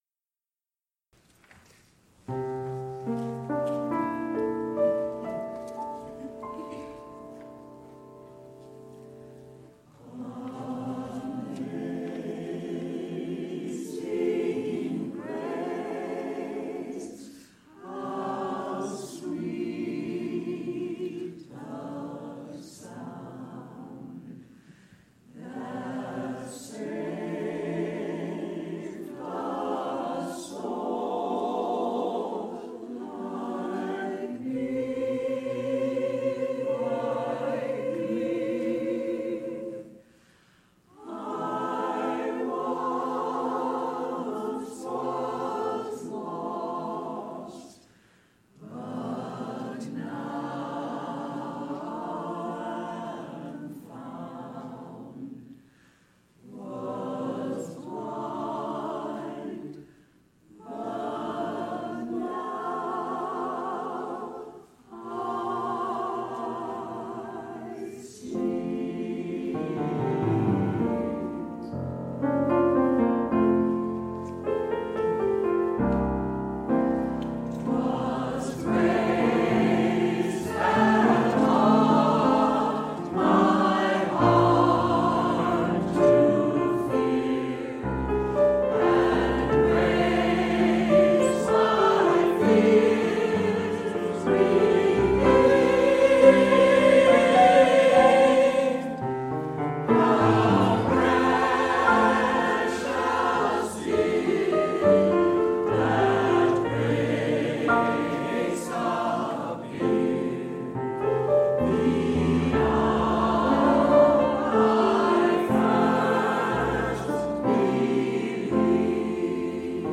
Past QUUF Choir Recordings